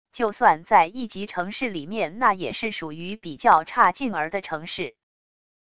就算在一级城市里面那也是属于比较差劲儿的城市wav音频生成系统WAV Audio Player